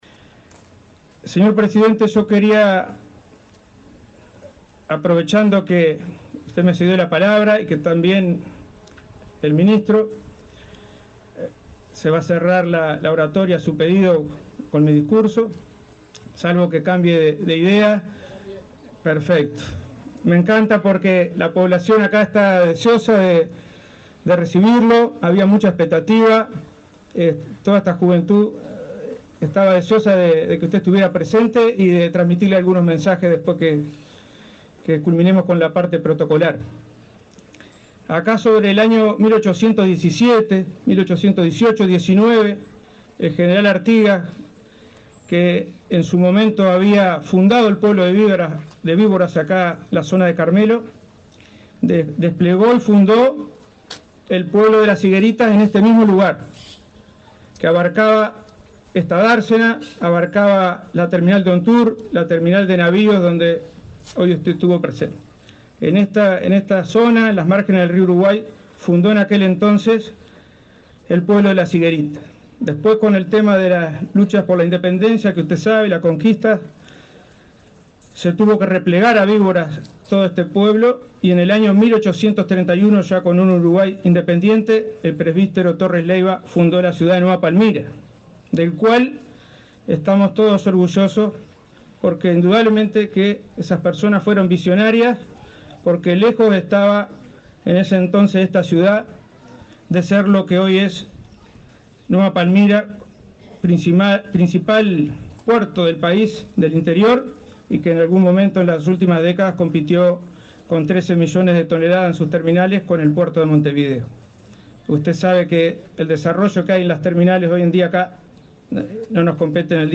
Palabras del director de Hidrografía del MTOP, Marcos Paolini 12/04/2024 Compartir Facebook Twitter Copiar enlace WhatsApp LinkedIn El director de Hidrografía del Ministerio de Transporte y Obras Públicas (MTOP), Marcos Paolini, disertó durante la inauguración de la terminal fluvial internacional de pasajeros del puerto de Nueva Palmira, en el departamento de Colonia.